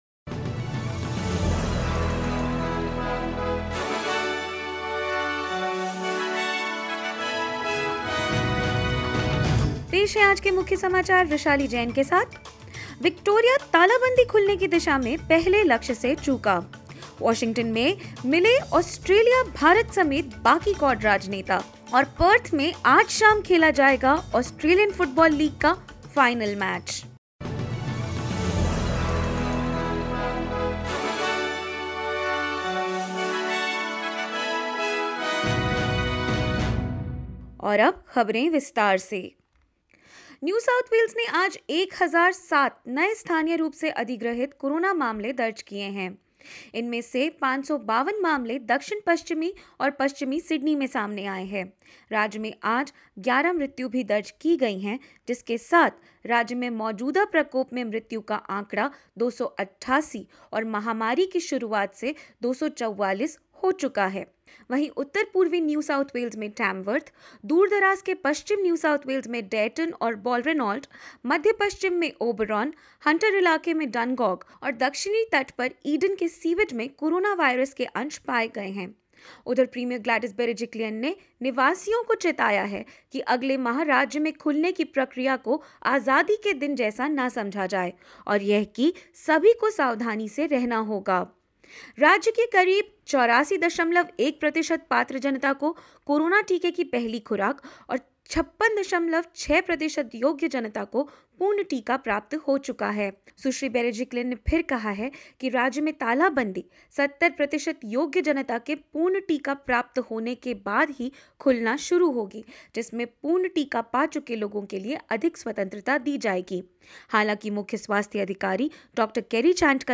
In this latest SBS Hindi News bulletin of Australia and India: Victoria registers its highest daily record of 847 coronavirus cases whereas New South Wales records 11 corona deaths; Rate of vaccination in Australian detention centres found to be lower than the national average and more.
2509_hindi_news.mp3